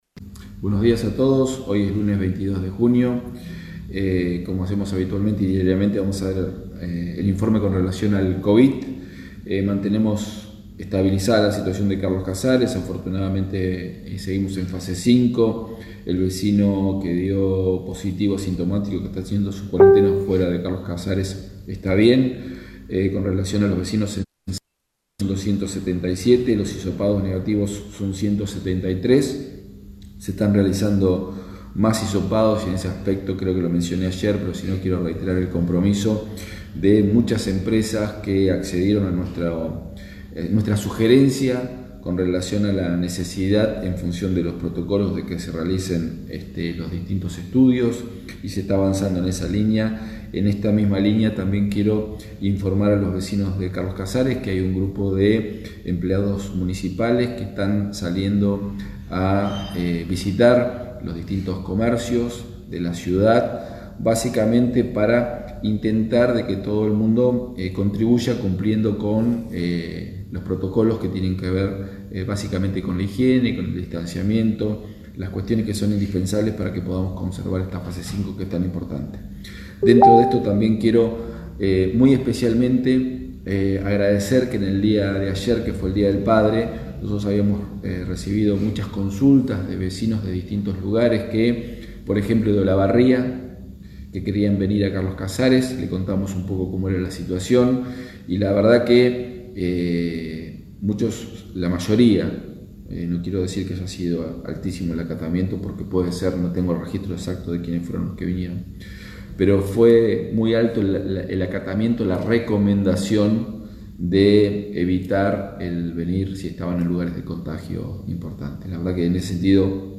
Lo adelantó el intendente Walter Torchio al hacer el informe diario sobre el coronavirus